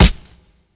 Kick (Enjoy Right Now, Today).wav